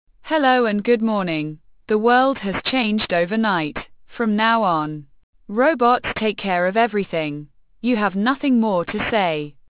Unterstützt werden Deutsch, Englisch, Schwedisch, Französisch und Spanisch, wobei die englische Stimme derzeit nur mit britischem Akzent verfügbar ist.
Klangbeispiel eines deutschen Textes als MP3: Die Stimme ist bei Google gTTS immer weiblich.